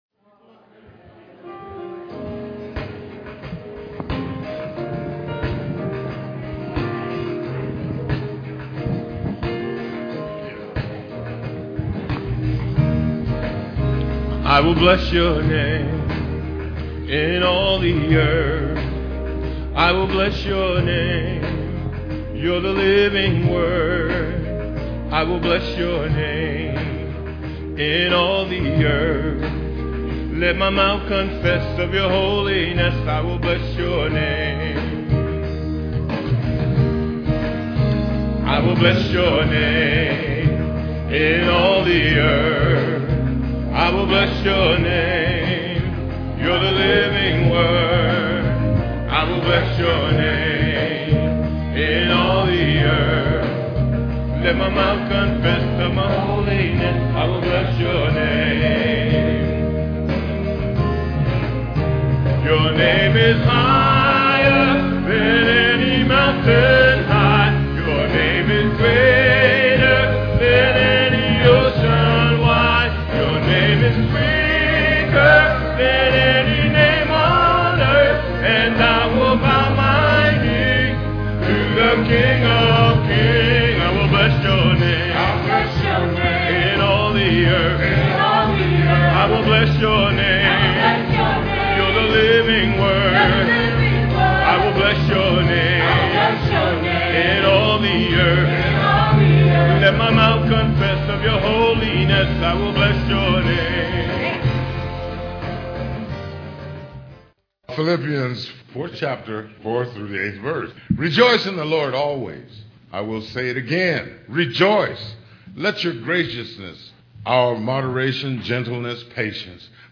Thank You Medley presented by the ABC Singers.